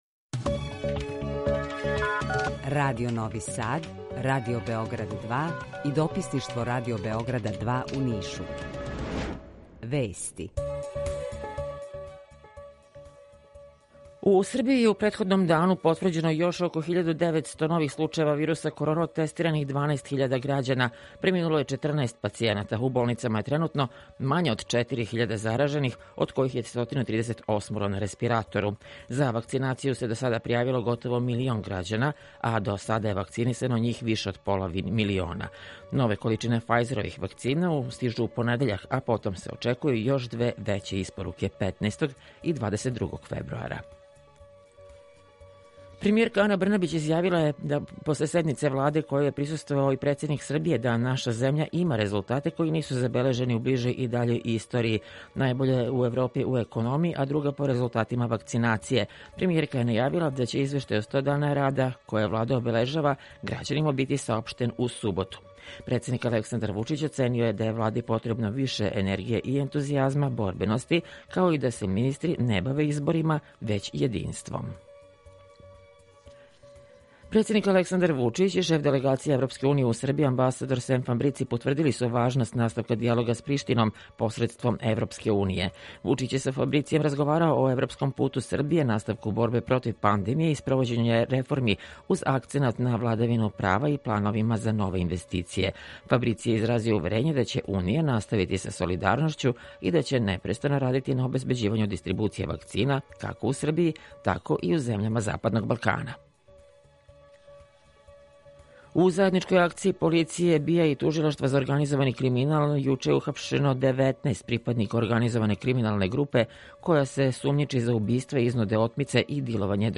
Укључење Радија Бањалука
Јутарњи програм из три студија
У два сата, ту је и добра музика, другачија у односу на остале радио-станице.